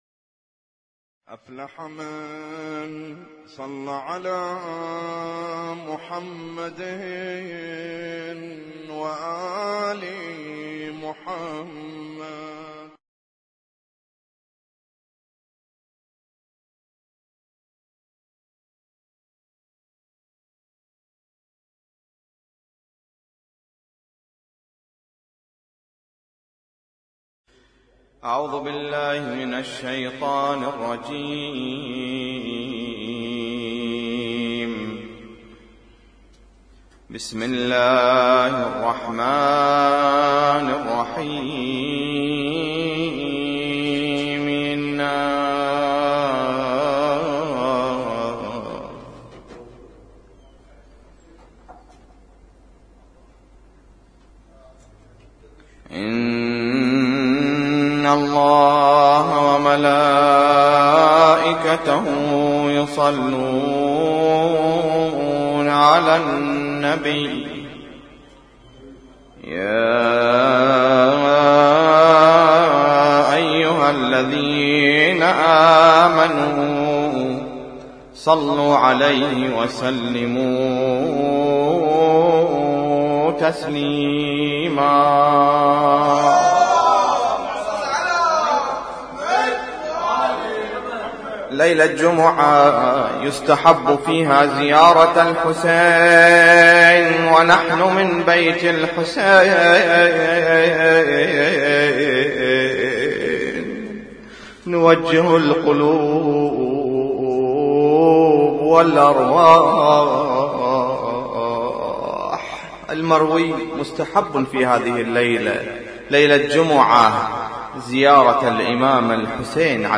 اسم التصنيف: المـكتبة الصــوتيه >> الادعية >> دعاء كميل